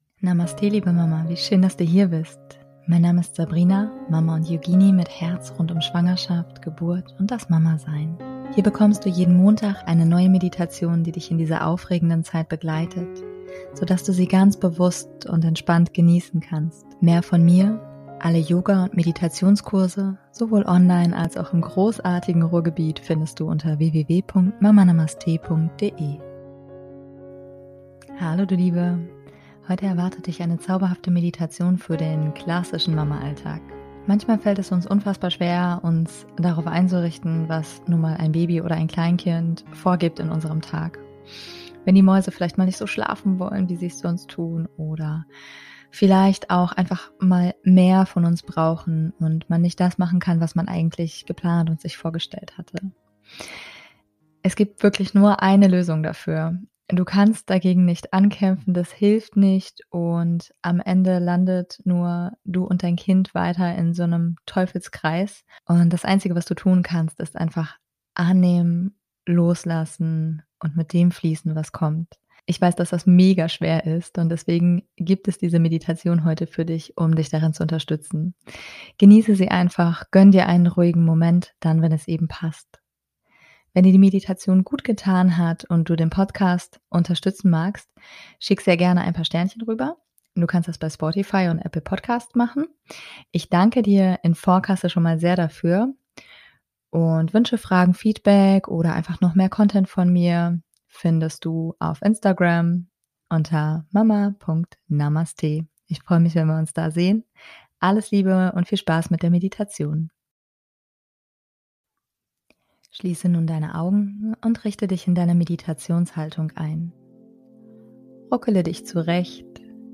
Heute erwartet dich eine zauberhafte Meditation für den klassischen Mama Alltag.